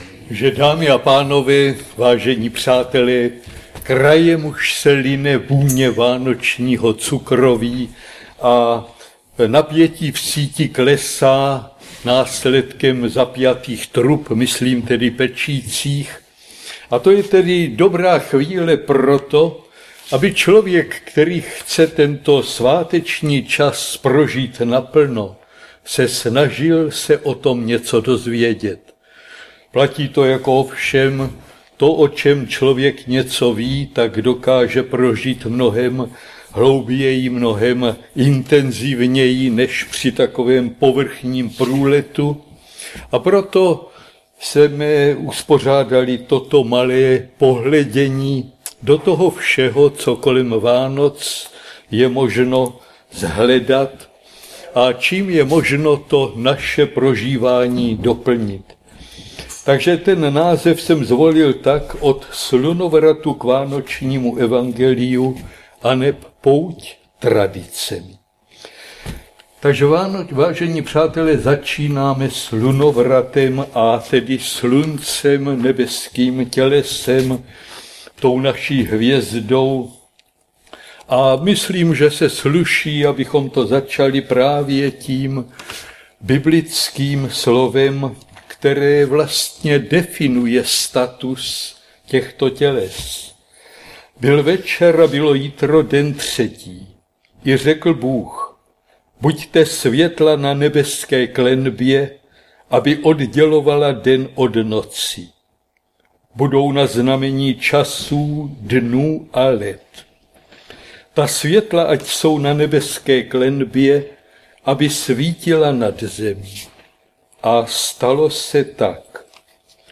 Záznam přednášky v mp3: